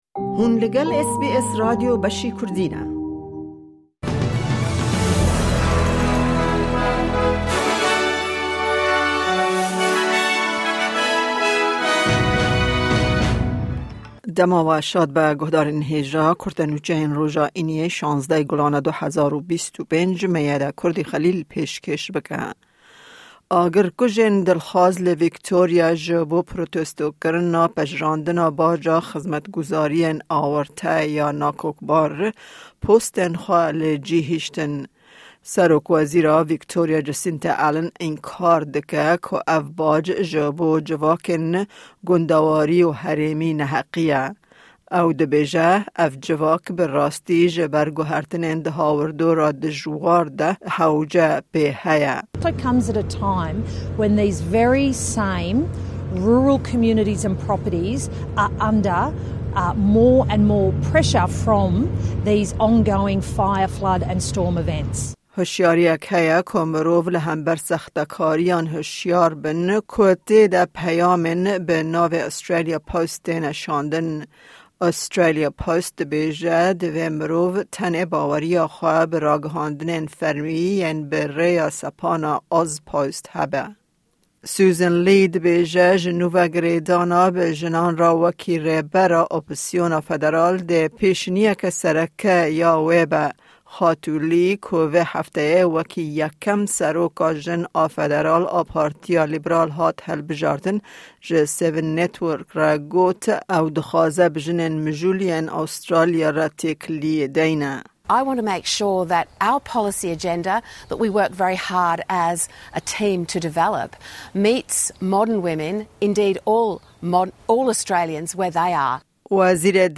Guhdare bûletena nûçeyên roja Îniyê bike: Agirkujên dilxwaz li Victoria ji bo protestokirinê postên xwe terikandin... Australia Post huşyariyê derbarê peyamên xapandinê dide...